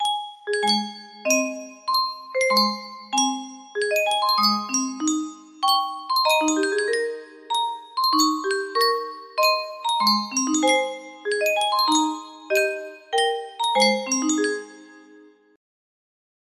Yunsheng Music Box - Oh My Darling Clementine Y862 music box melody
Full range 60